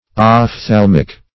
ophthalmic - definition of ophthalmic - synonyms, pronunciation, spelling from Free Dictionary
Ophthalmic \Oph*thal"mic\ ([o^]f*th[a^]l"m[i^]k; 277), a. [Gr.